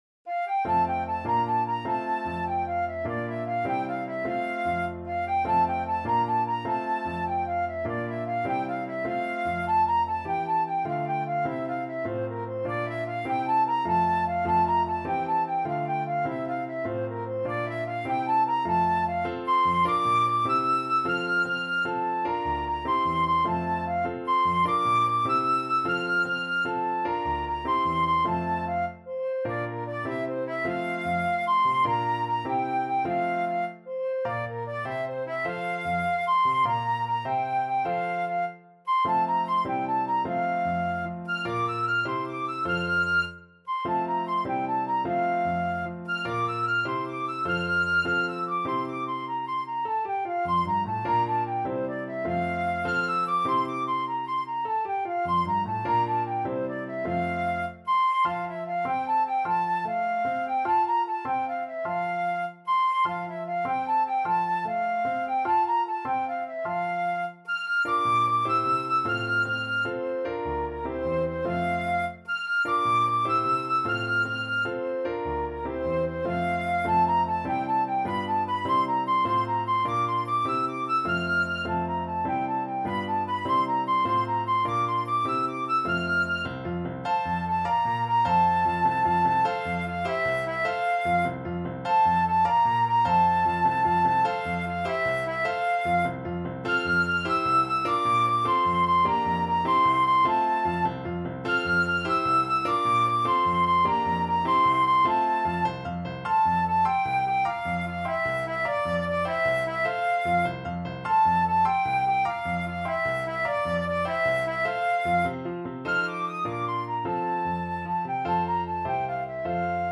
6/8 (View more 6/8 Music)
Classical (View more Classical Flute Music)